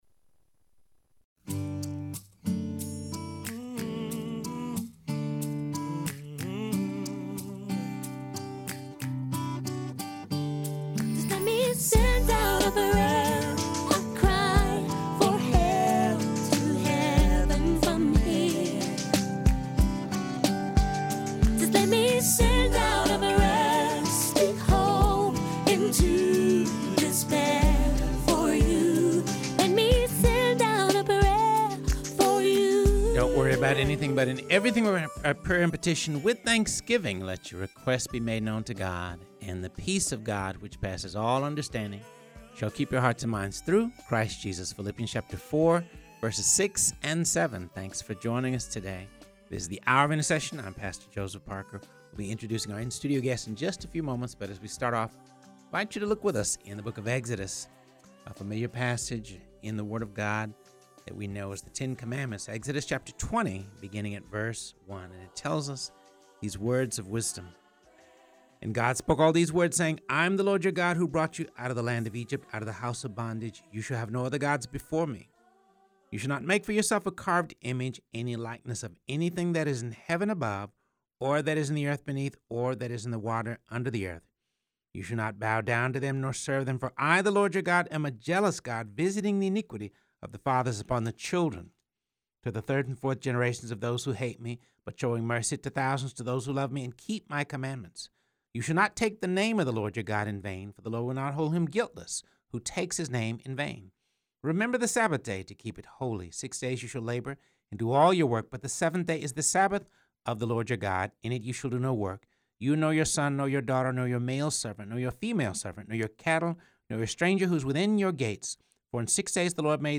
in studio to talk about hope and healing for post-abortive mothers through the program, Surrendering the Secret.